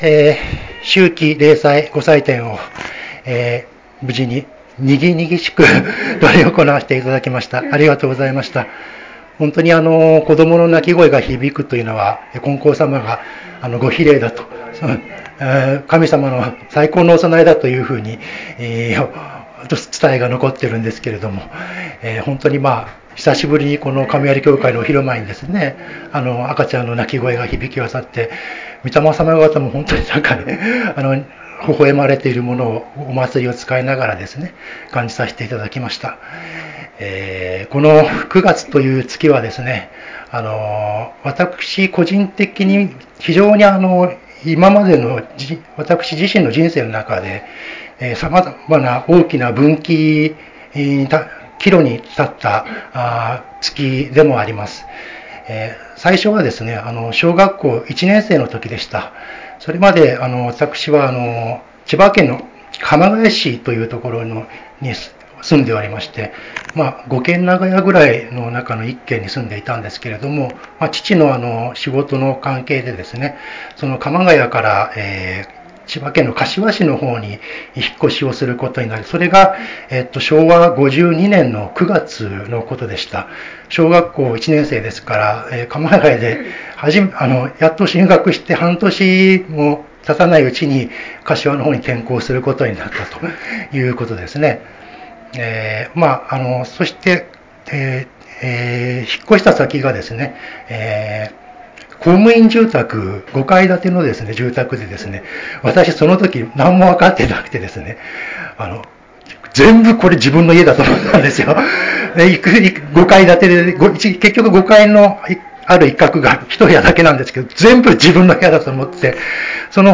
秋季霊祭教話